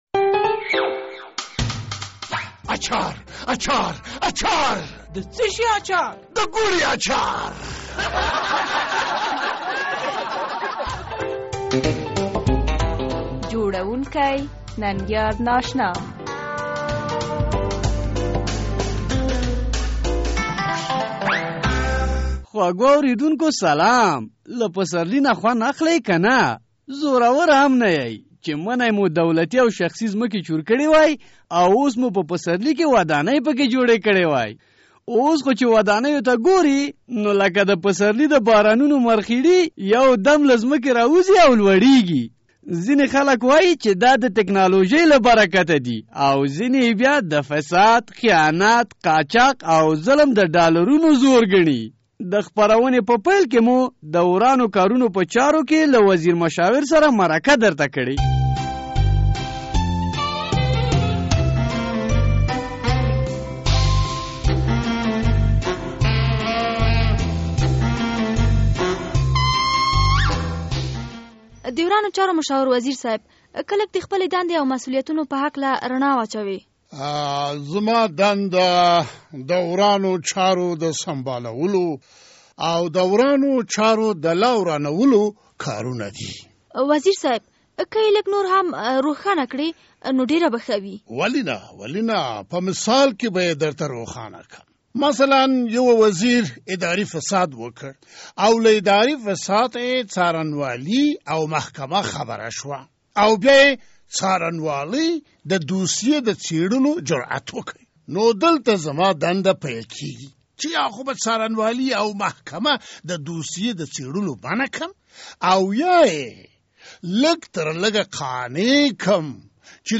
د ازادي راډیو د اوونۍ طنزي خپرونه د ګوړې اچار